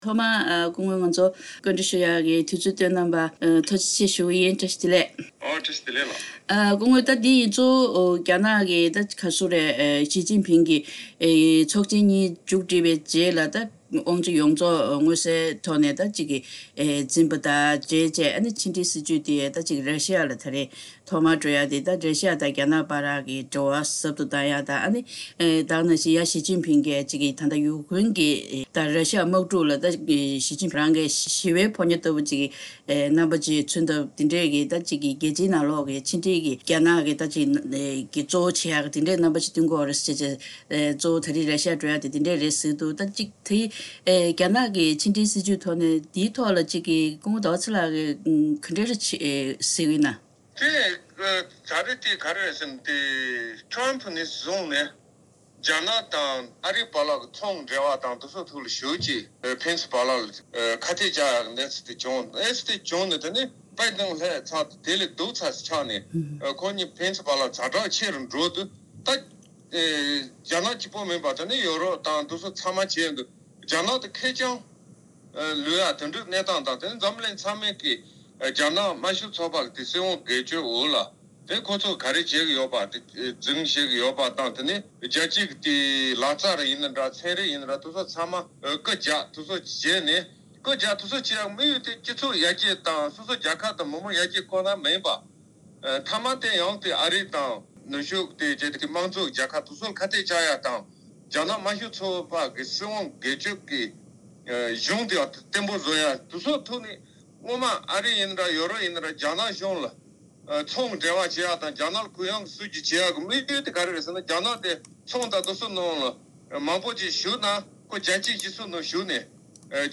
བཀའ་འདྲི་ཞུས་པ་ཞིག་སྙན་སྒྲོན་ཞུས་གནང་གི་རེད།